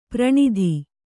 ♪ praṇidhi